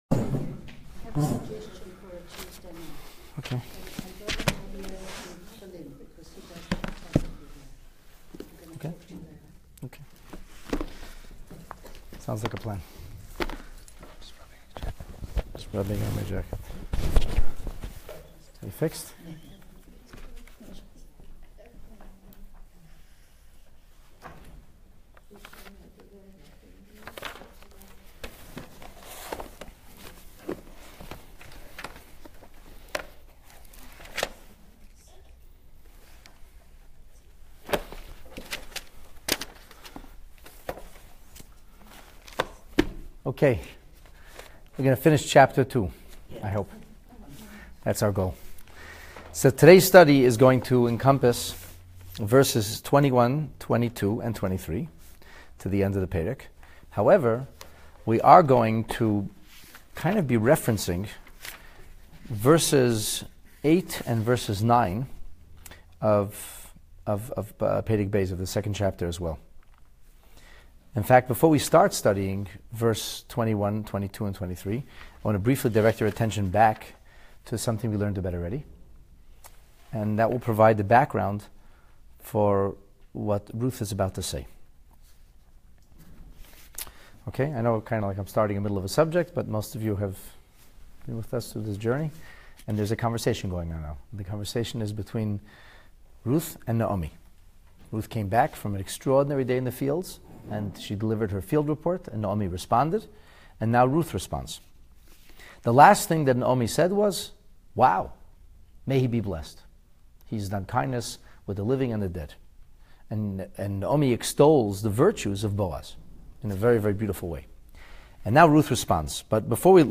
Megillat Rut in Depth: Chapter 2, Lesson 10 (PT 18) Moabite Misgivings!
Is this indicative of moral regression, and why did she change key details in recounting Boaz’s generous offer? This class richly illustrates realistic virtues of character development and highlights the true meaning of ‘relationship integrity.’